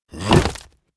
behemoth_attack1c.wav